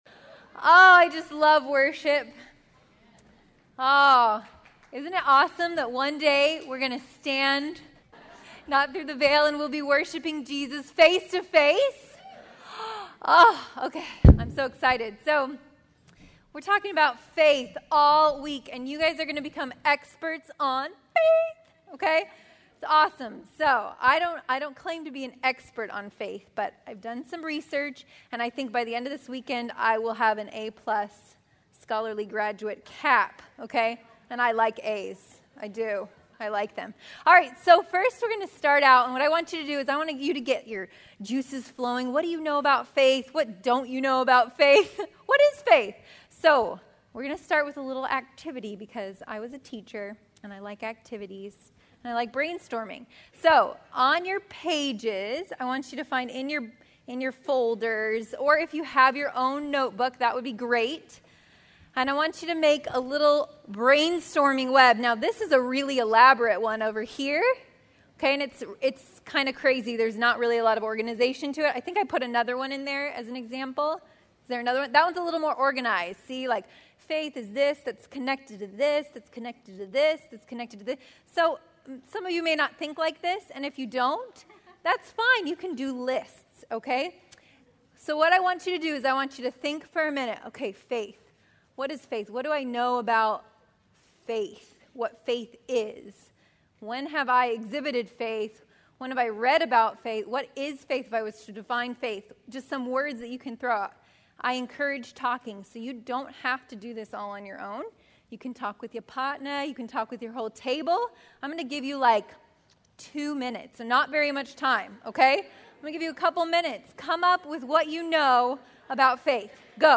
2012 Women's Retreat - Calvary Tucson Church